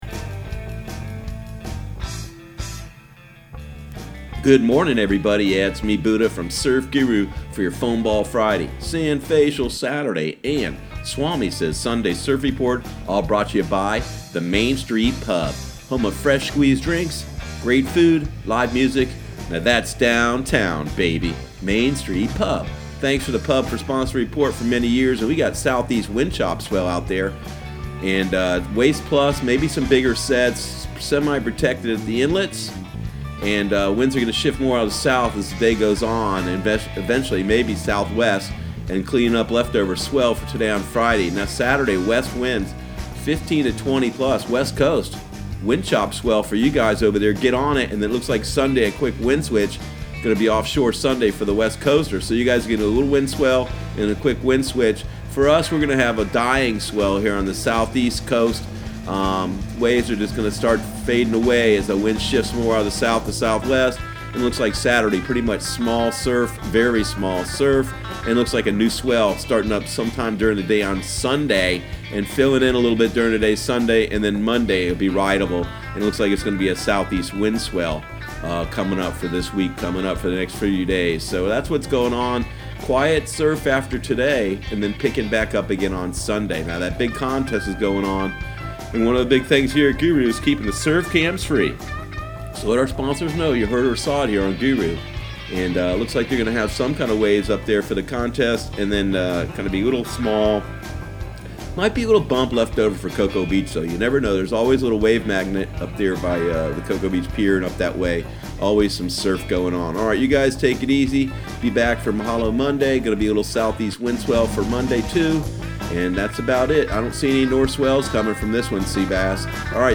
Surf Guru Surf Report and Forecast 04/19/2019 Audio surf report and surf forecast on April 19 for Central Florida and the Southeast.